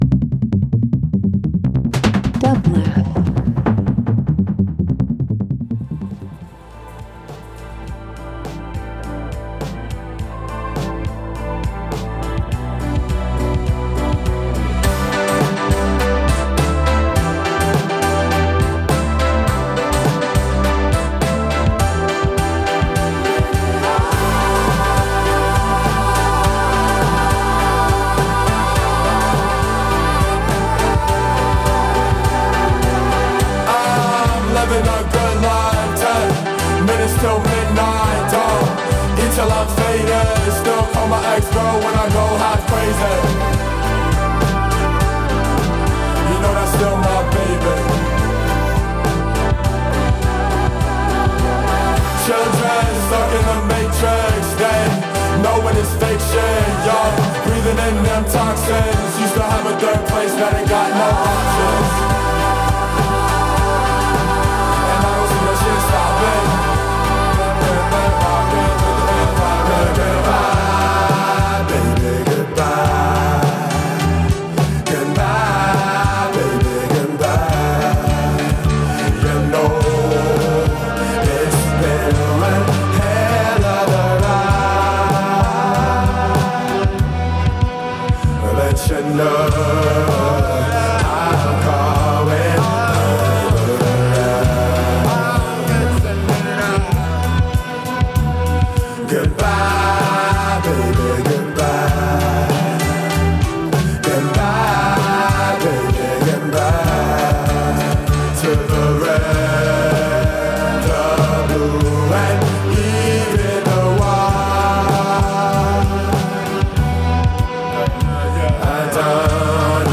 LIVE FROM THE LOFT @ PORTER STREET STUDIO
Disco Electronic Fourth World Funk/Soul